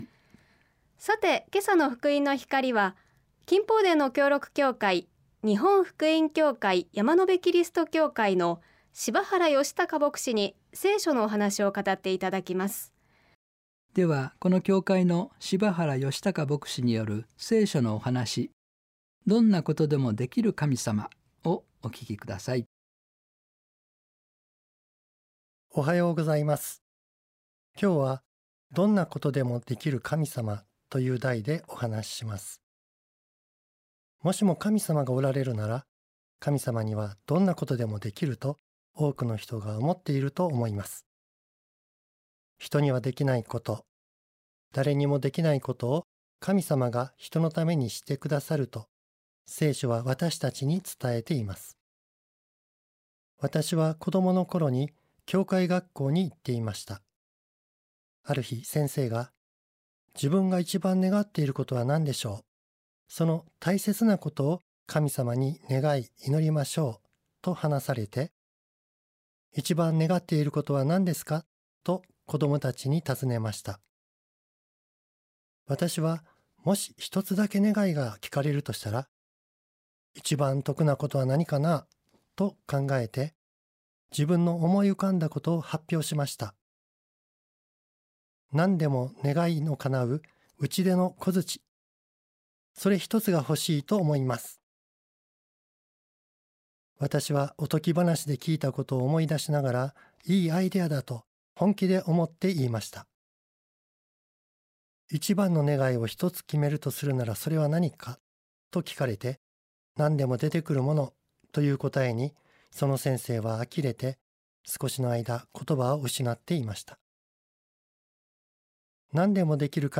聖書のお話